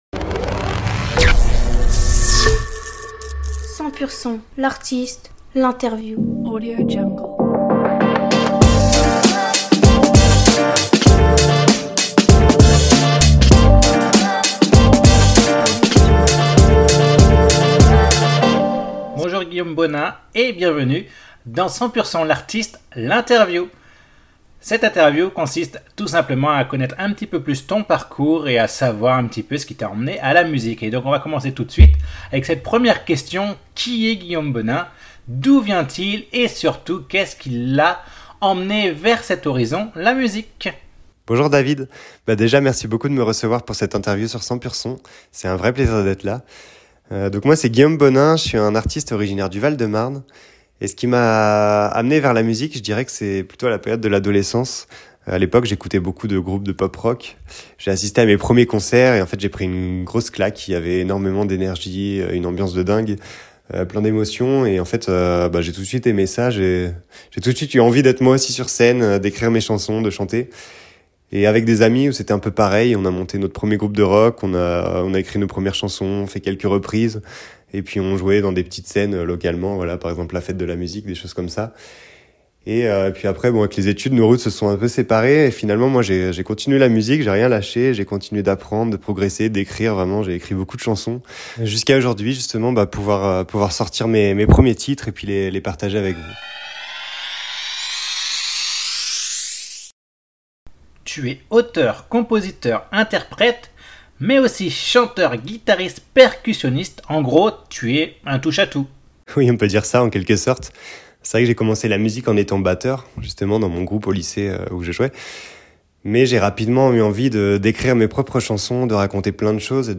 100Purson L'Interview